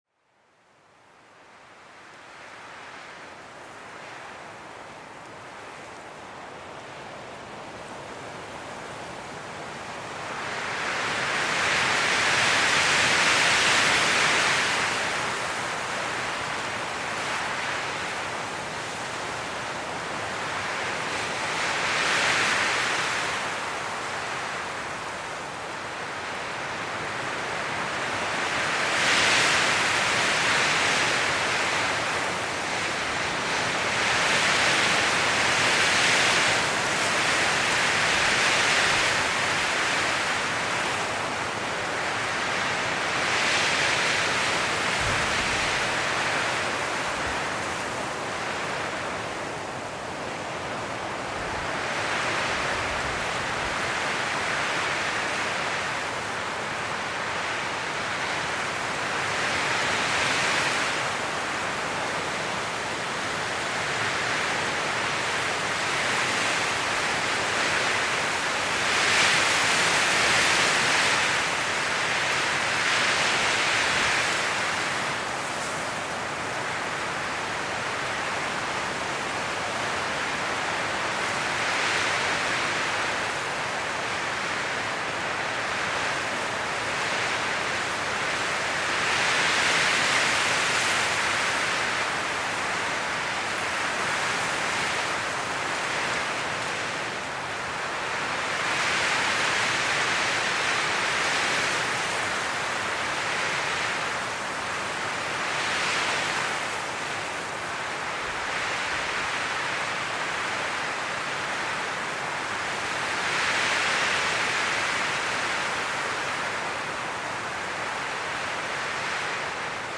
STRESS-KILLER: Bergwind-Ritual mit verschneiten Böen